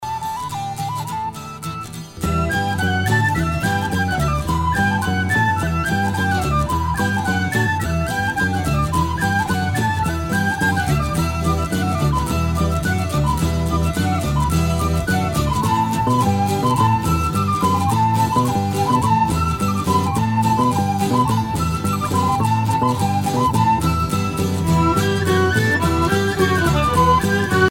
Groupe celtique